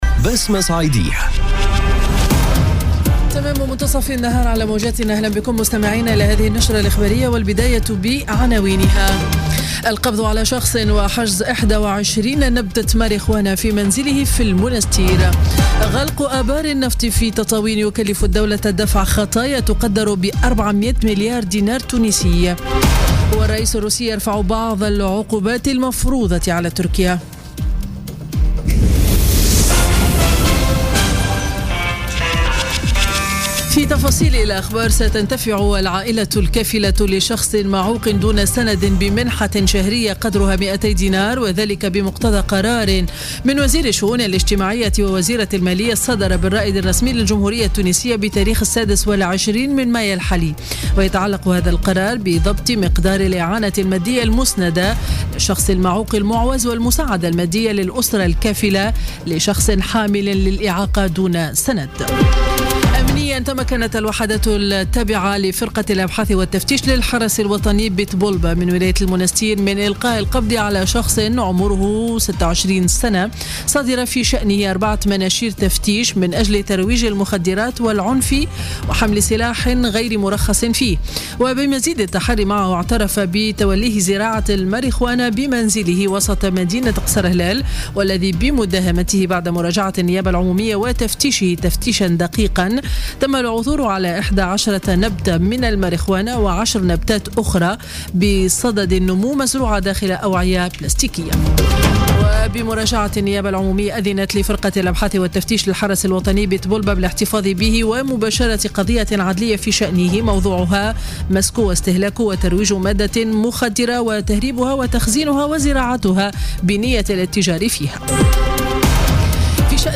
نشرة أخبار منتصف النهار ليوم الإربعاء 31 ماي 2017